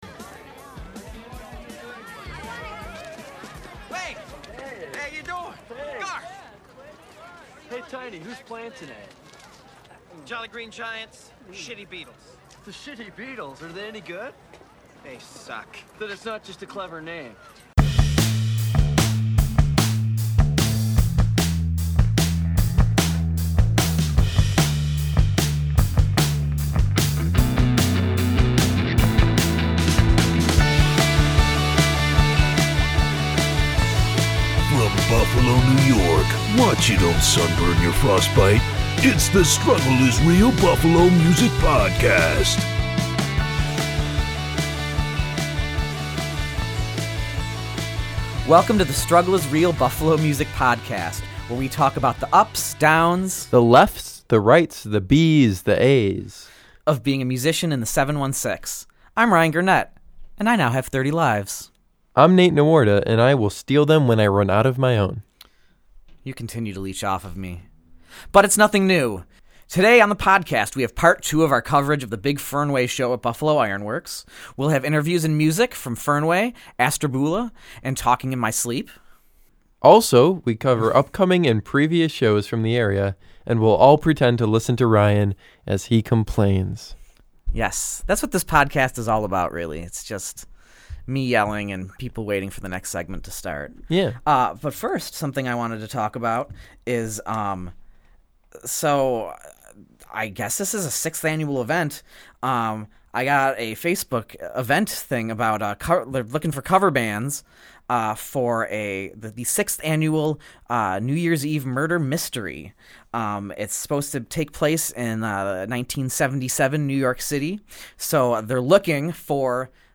This is the Second of two episodes recorded at Iron Works.